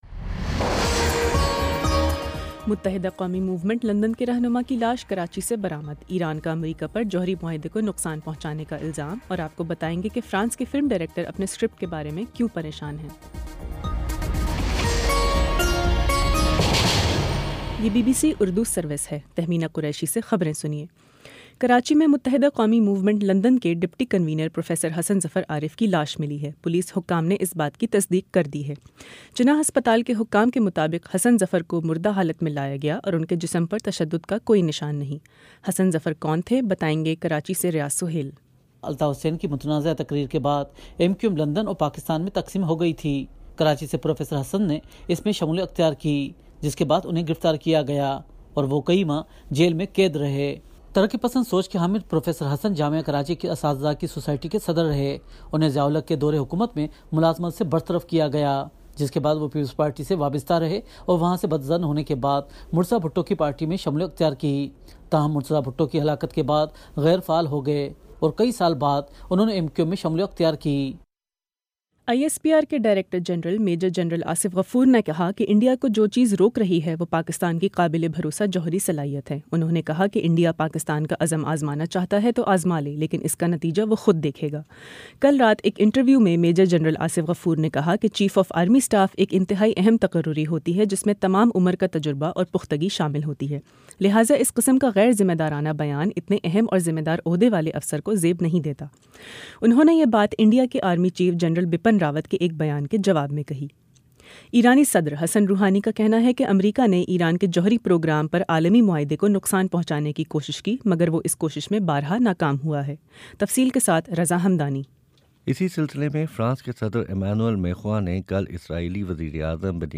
جنوری 14 : شام سات بجے کا نیوز بُلیٹن